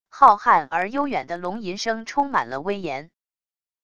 浩瀚而悠远的龙吟声充满了威严wav音频